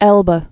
(ĕlbə, ĕlb)